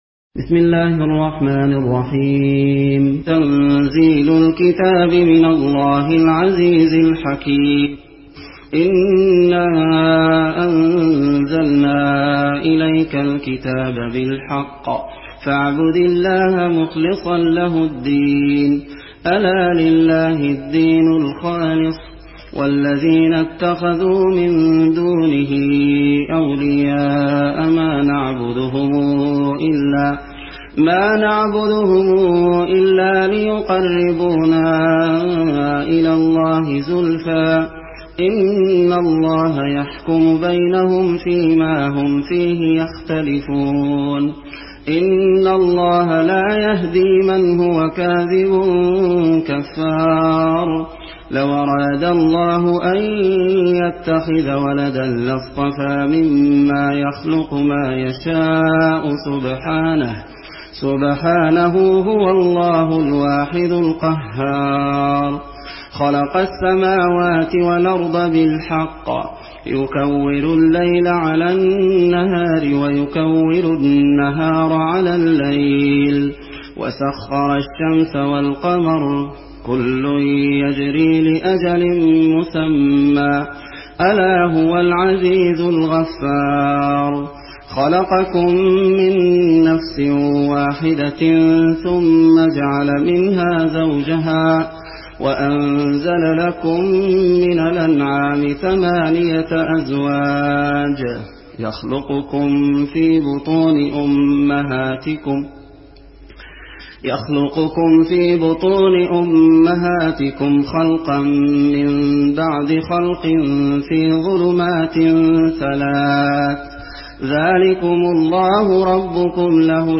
(উপন্যাস Warsh)